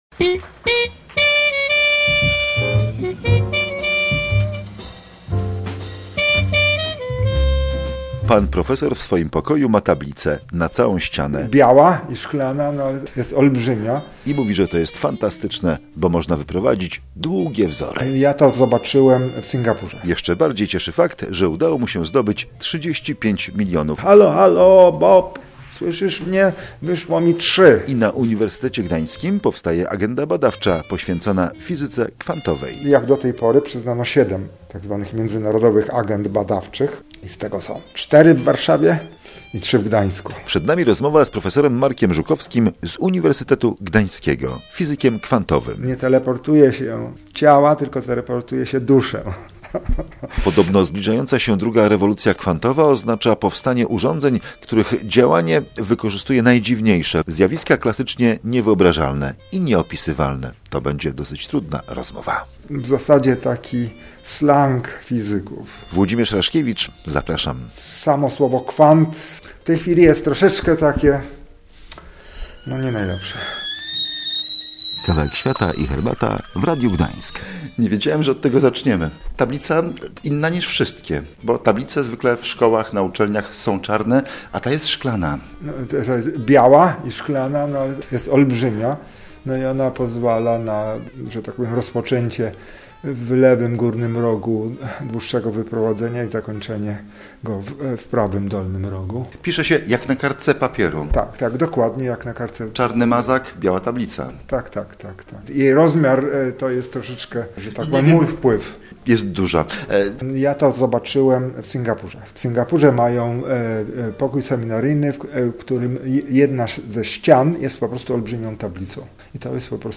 Na UG prace będą dotyczyły zagadnień mechaniki kwantowej, na GUMed chorób nowotworowych. W audycji gościem